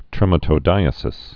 (trĕmə-tō-dīə-sĭs)